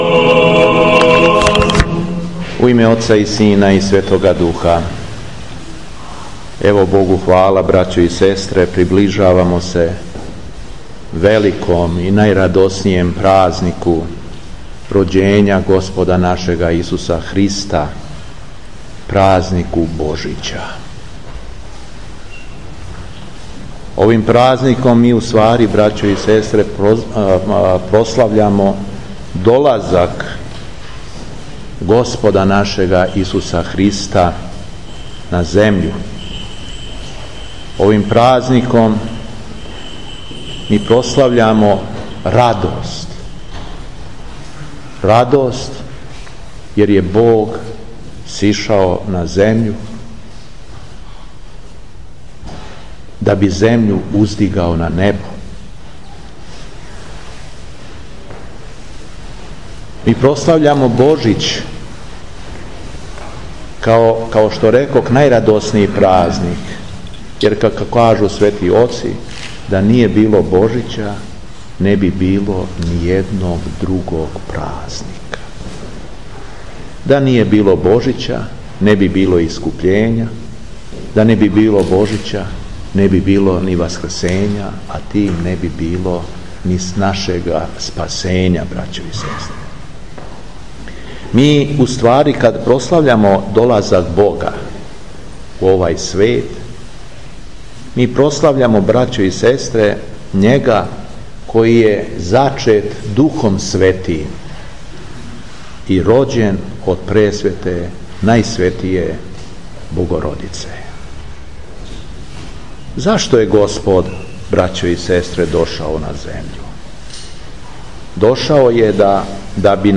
Беседа Епископа шумадијског Г. Јована
Обраћајући се окупљеним верницима у препуном храму вреочке светиње шумадијски Првојерарх се осврнуо на радост празника Божића, којем идемо у сусрет.